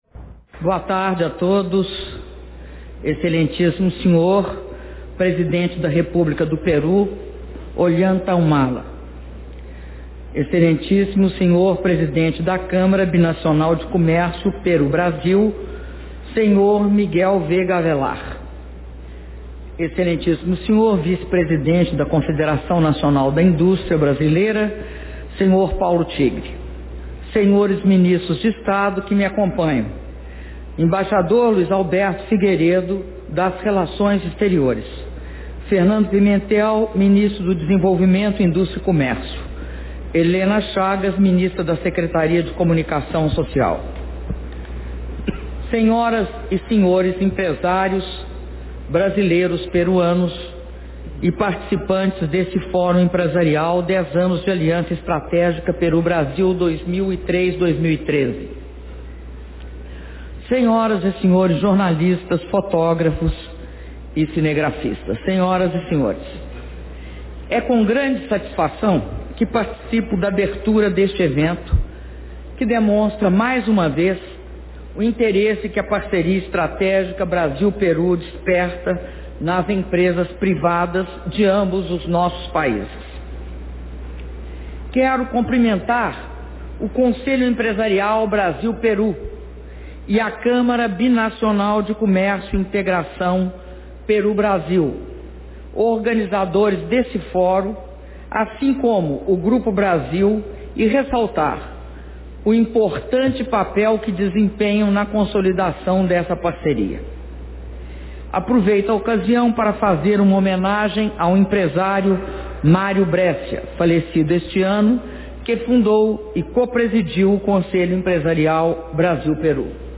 Áudio do discurso da Presidenta da República, Dilma Rousseff, na cerimônia de abertura do Foro Empresarial Brasil-Peru- Lima/Peru